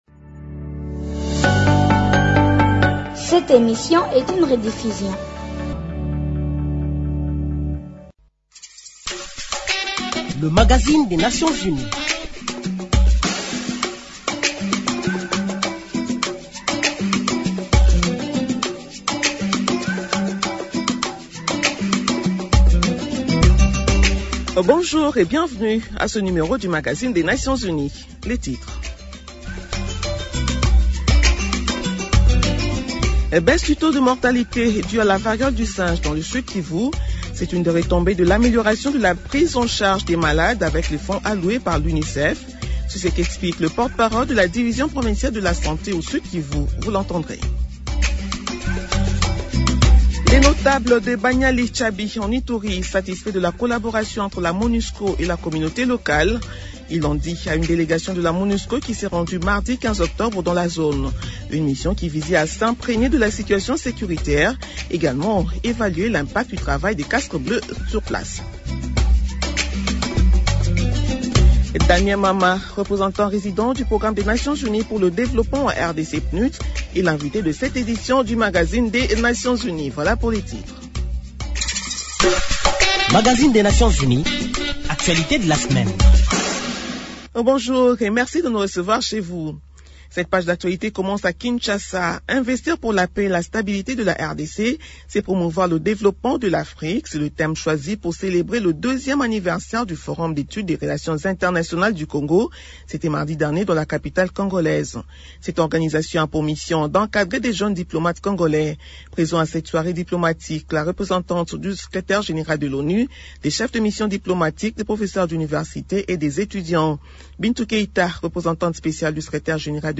Nouvelles en bref -Le Programme alimentaire mondial (PAM) affirme dans une déclaration que la journée mondiale de l’alimentation revêt une importance particulière pour le Nord-Kivu.
Invité Le Magazine des Nations unies reçoit comme invité Damien Mama, Représentant résident du Programme des nations unies pour le développement en RDC (PNUD).